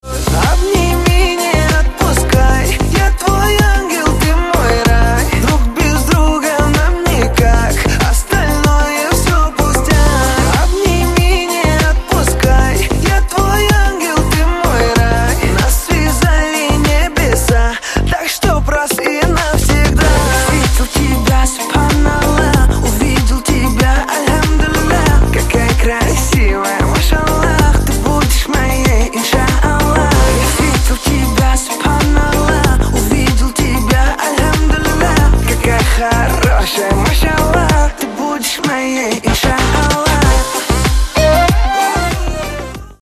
• Качество: 128, Stereo
поп
мужской вокал
dance
татарские